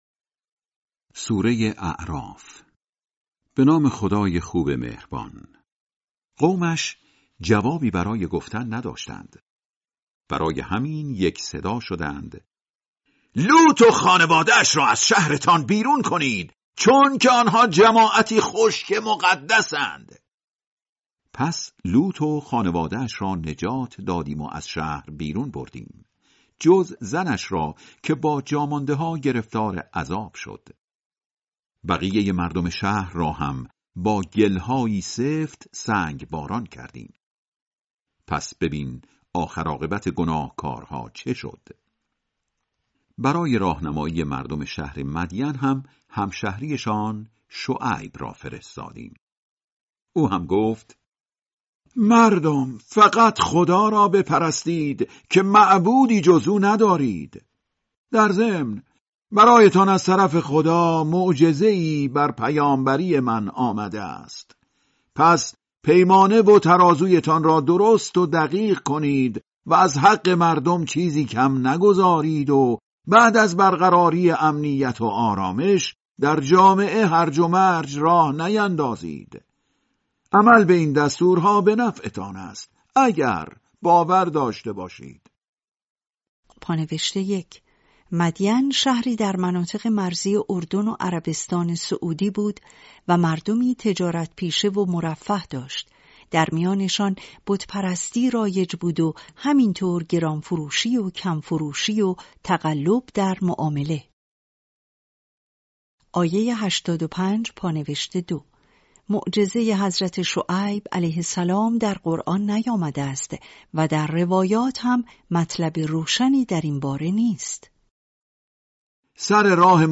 ترتیل سوره(اعراف)